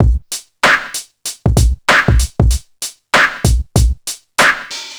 • 96 Bpm Drum Groove A# Key.wav
Free drum beat - kick tuned to the A# note. Loudest frequency: 1651Hz
96-bpm-drum-groove-a-sharp-key-EFb.wav